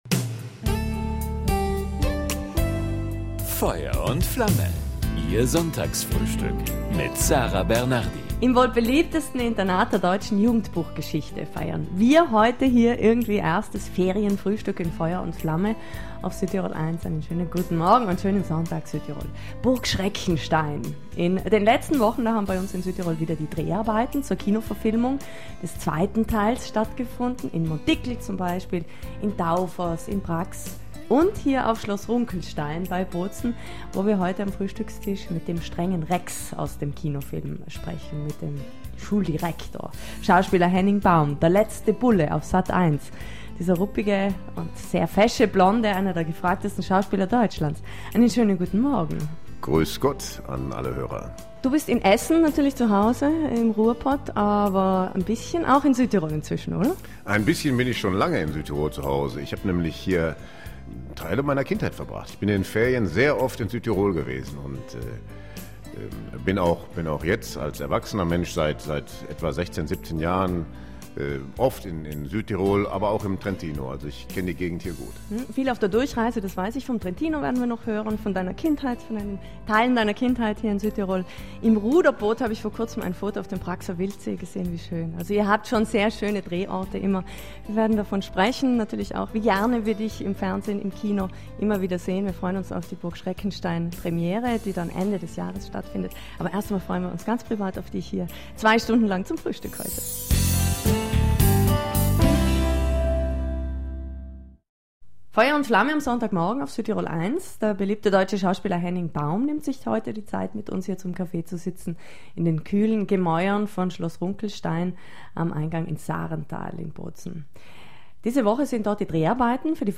Zum Nachhören: Schauspieler Henning Baum in "Feuer und Flamme"
Das und vieles mehr hat er im Südtirol1-Sonntagsfrühstück natürlich auch erzählt...